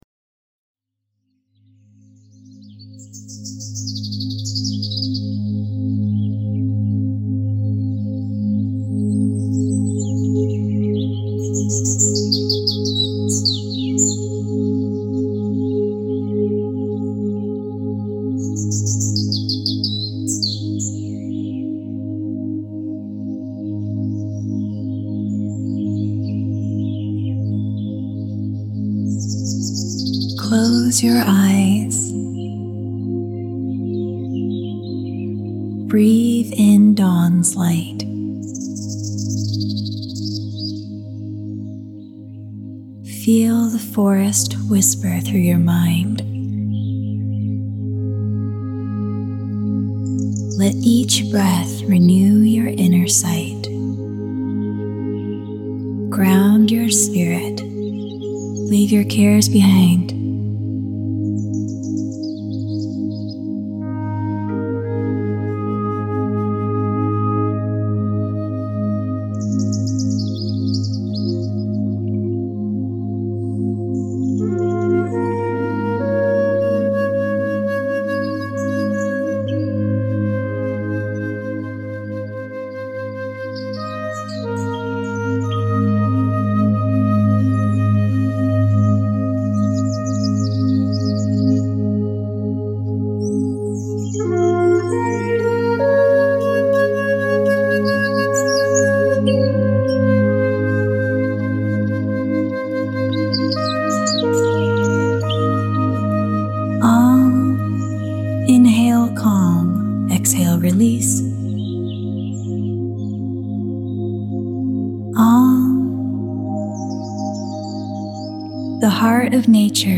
morning birds with light meditation tones.